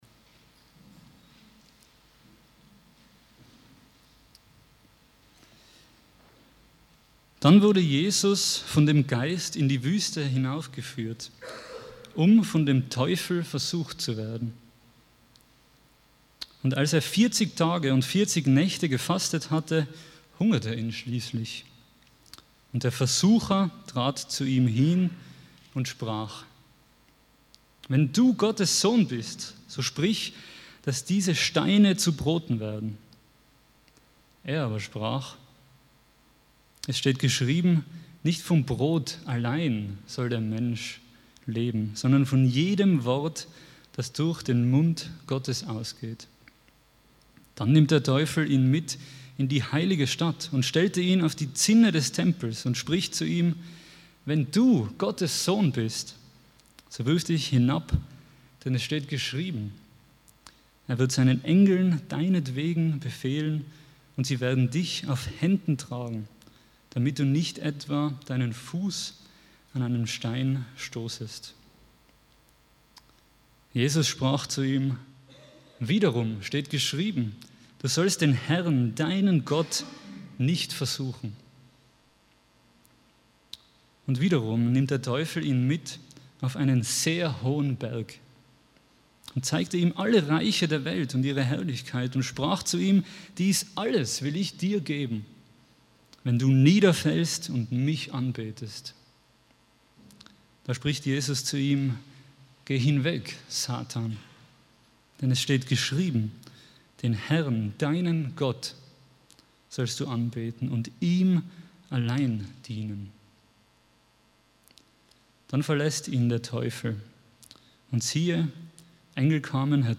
Predigtreihe